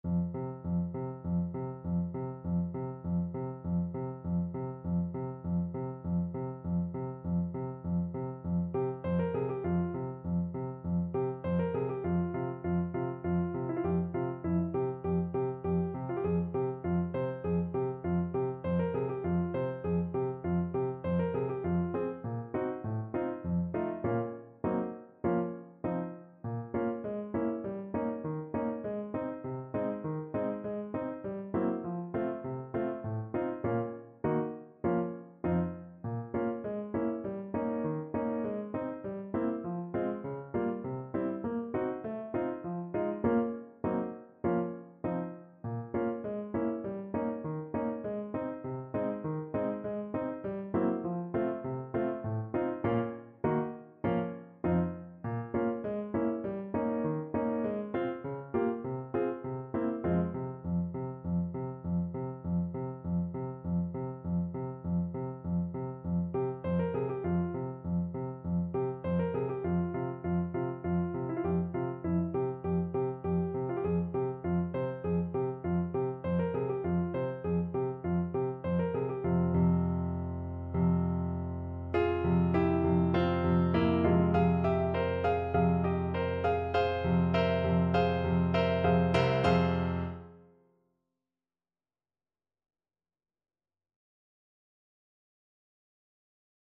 Czajkowski: Taniec łabędzi (na klarnet i fortepian)
Symulacja akompaniamentu